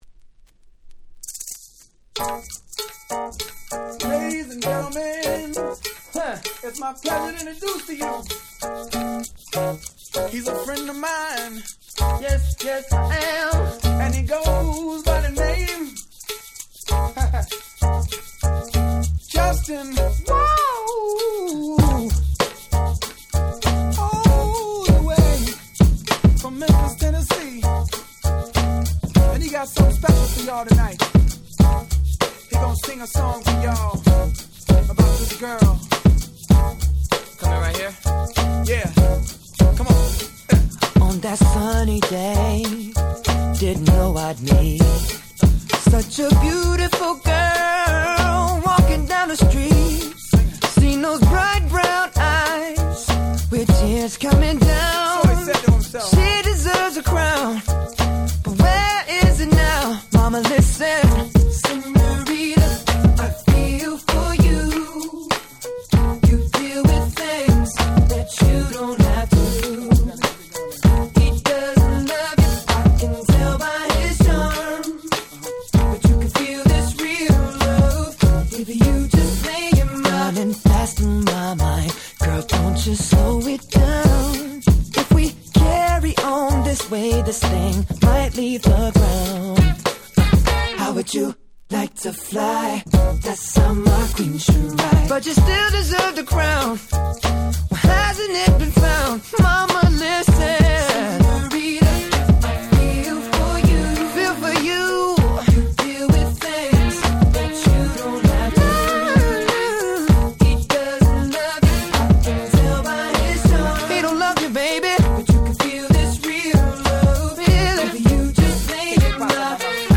03' Super Hit R&B !!